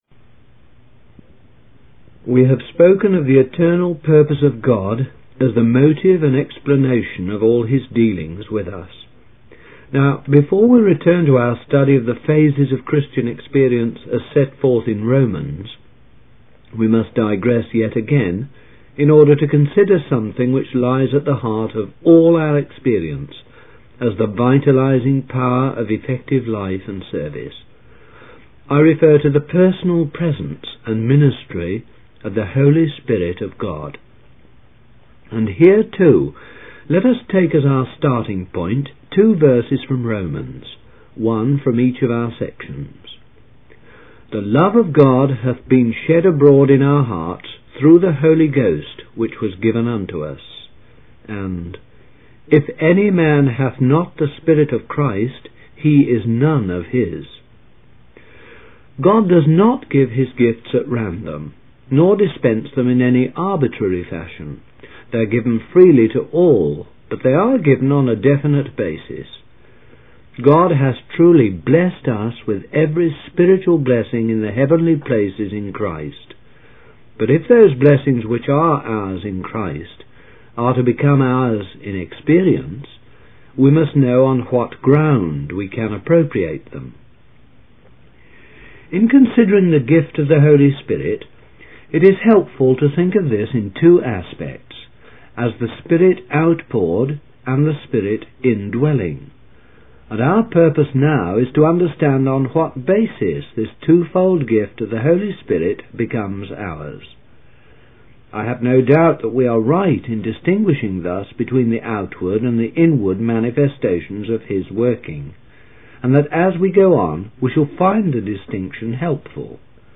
In this sermon, the preacher emphasizes the importance of repentance and baptism as conditions for receiving forgiveness of sins.